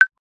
click-short-confirm.ogg